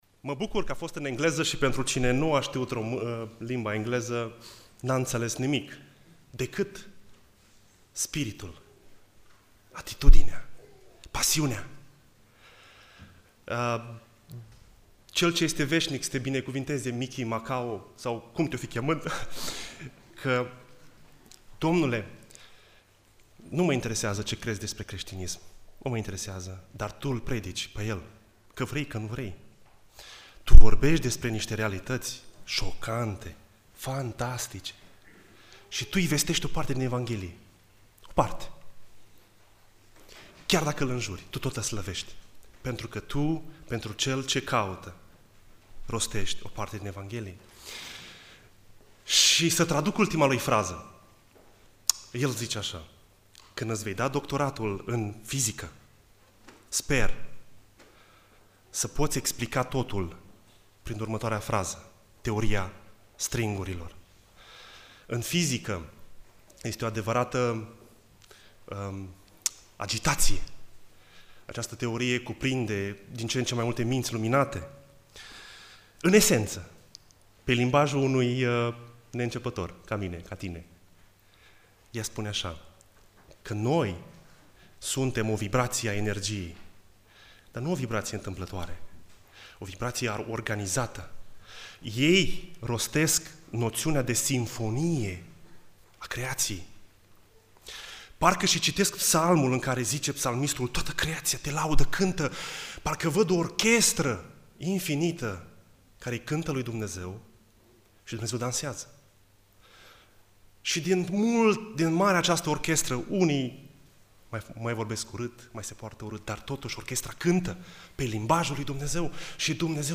Predica Exegeza 1 Timotei 4